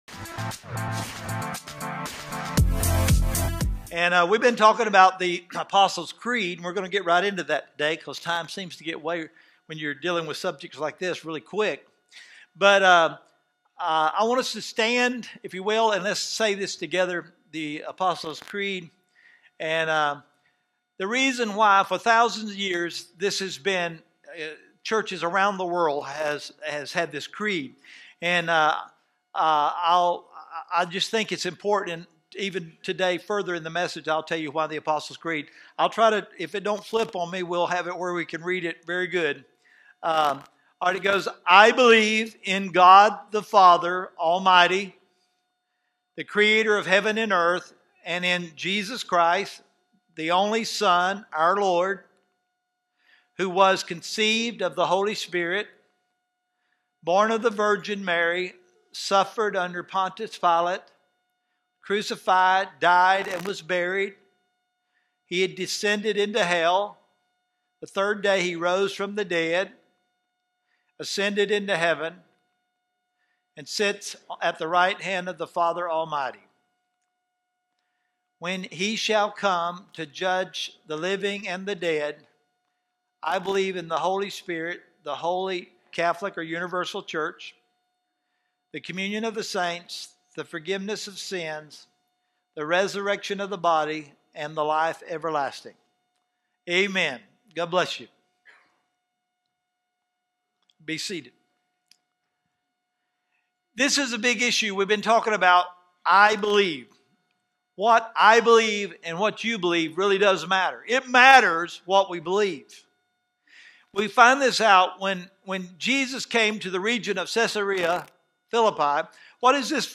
The next part of our sermon series